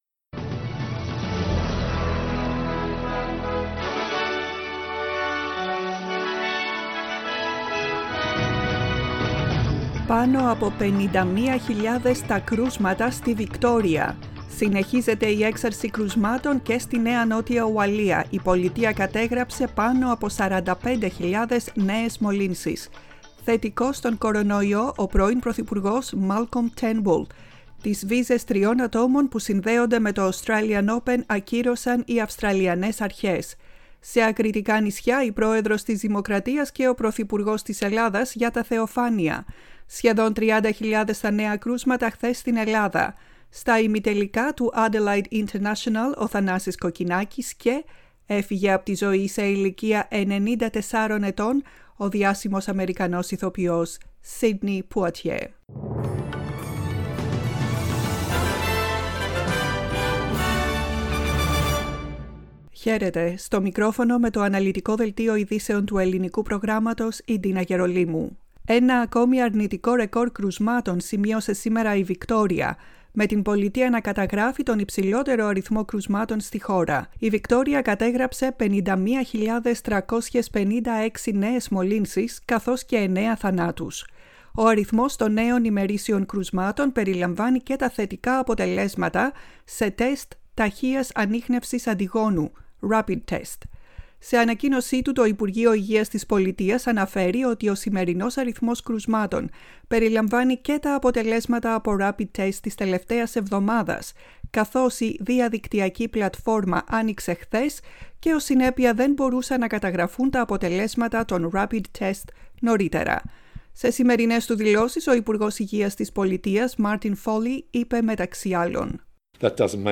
Δελτίο ειδήσεων, 08.01.22
Το κεντρικό δελτίο ειδήσεων απ΄το Ελληνικό Πρόγραμμα.